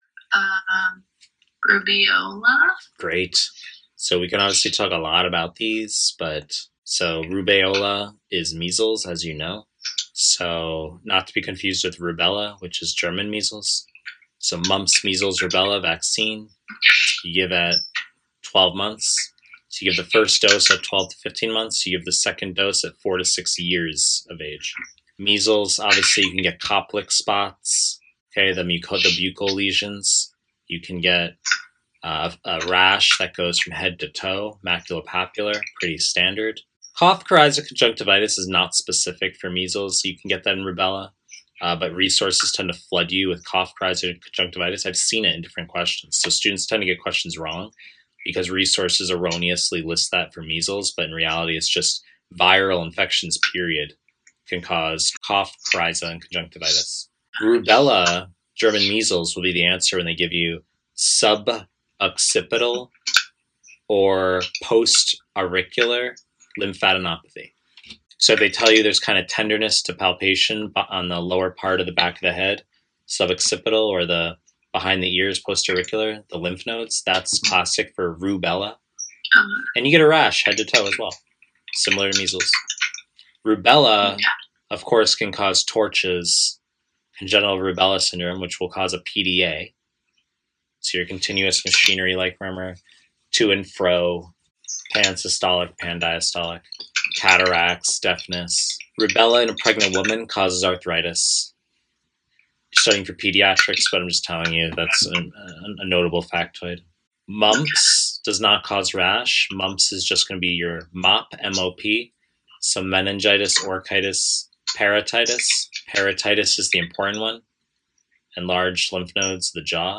Pediatrics / Pre-recorded lectures